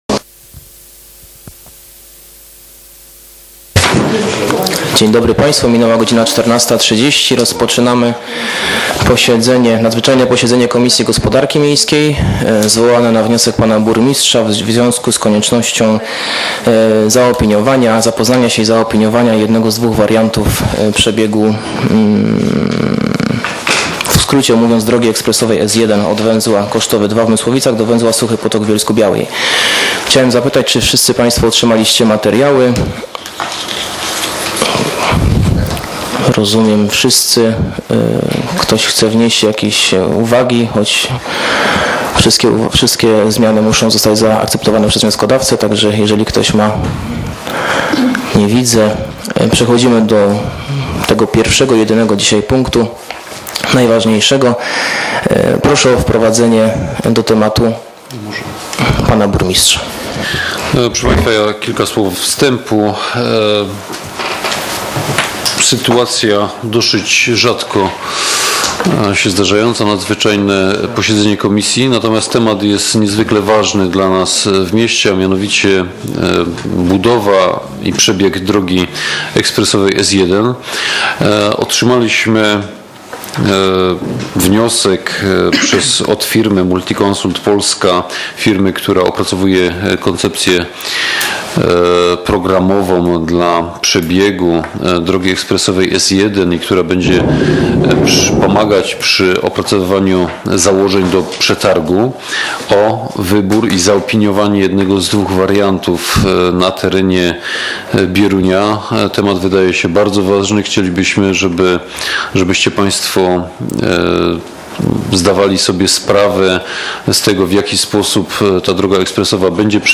z posiedzenia Komisji Gospodarki w dniu 10.12.2018 r.
Załączony plik „Nagranie” zawiera pełny przebieg komisji w wersji fonicznej.